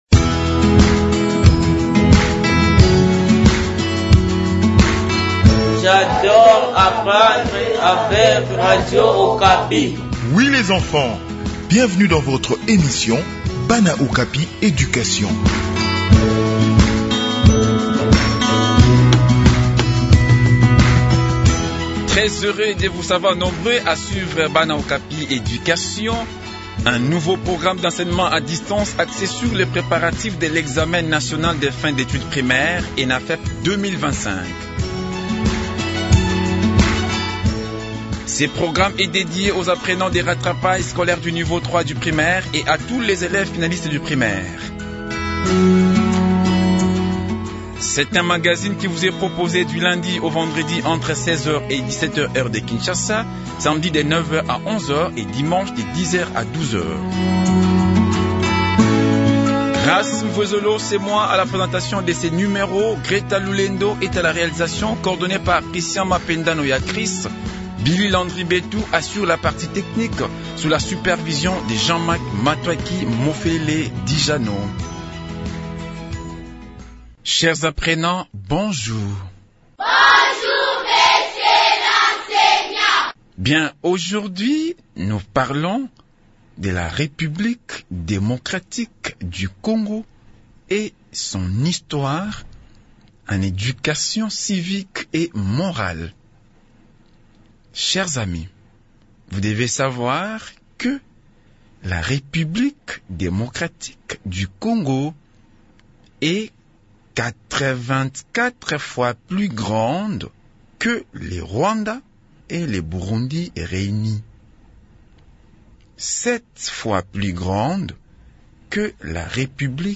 Préparation aux examens nationaux : leçon d'Histoire sur la RDC | Radio Okapi